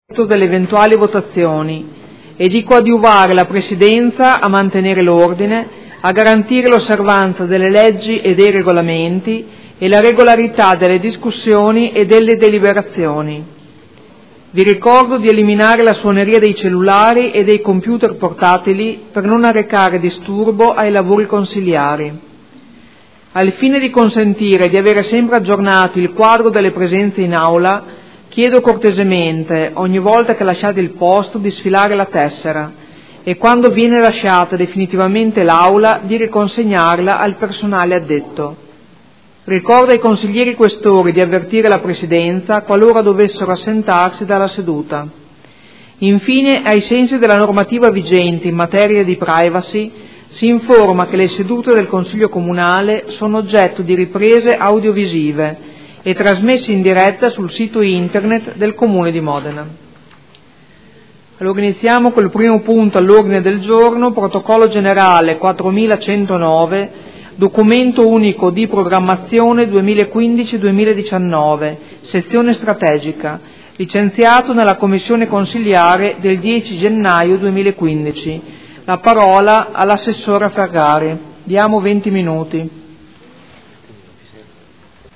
Apertura del Consiglio Comunale